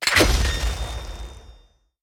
sword_critical.ogg